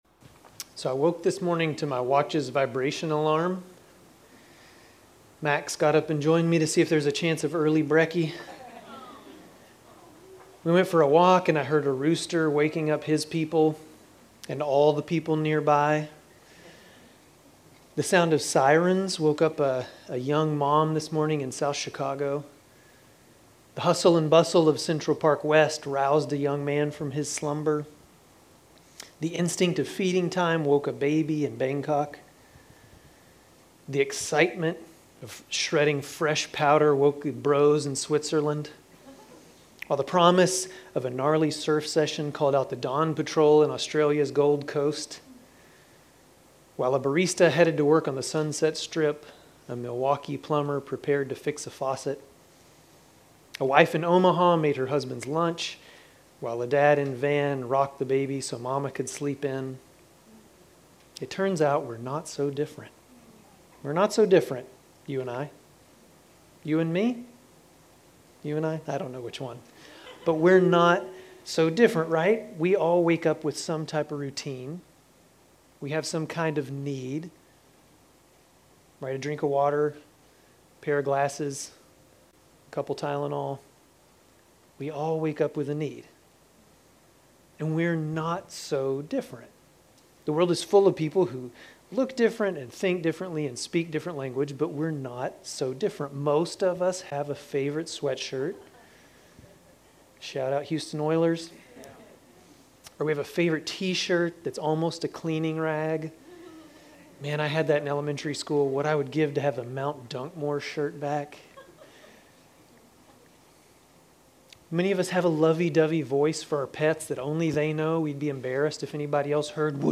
Grace Community Church Dover Campus Sermons 9_28 Dover Campus Sep 29 2025 | 00:31:02 1x 00:00 / 00:31:02 Subscribe Share RSS Feed Share Link Embed